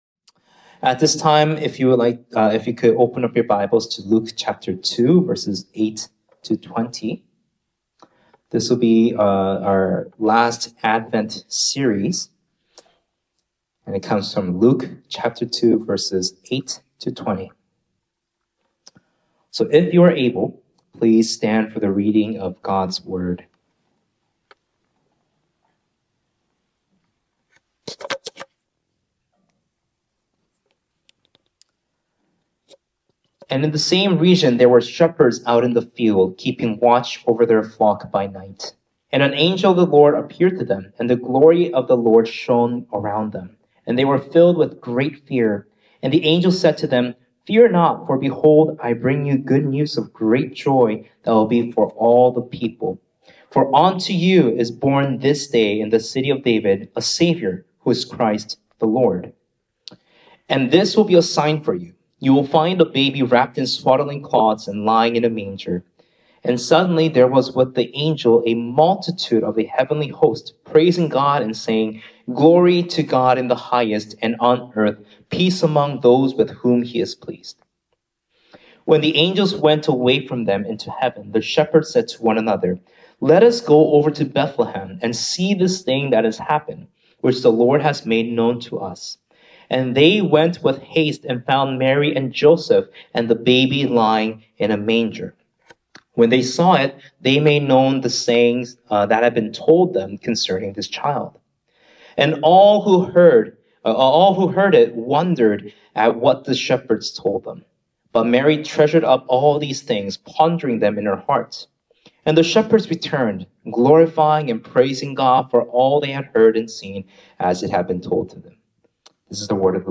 Find out more in this Christmas sermon.